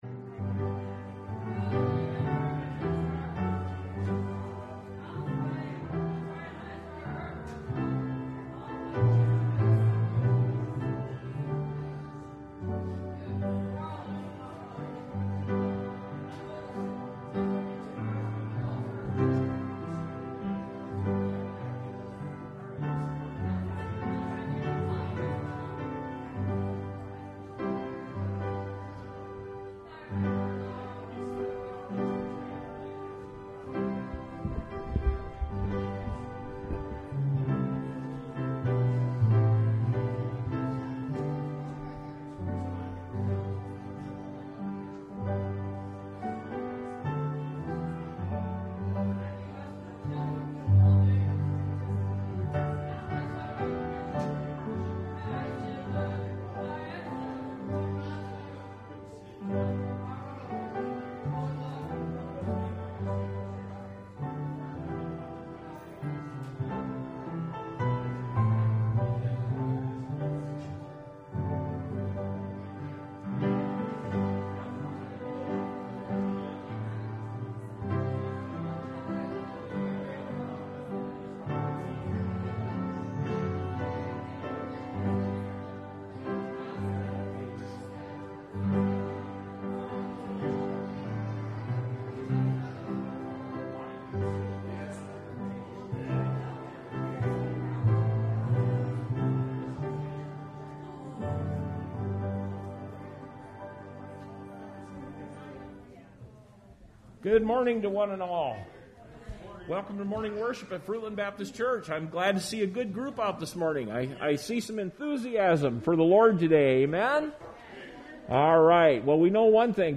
Service Type: Sunday Morning Outdoor Service Topics: Christian Living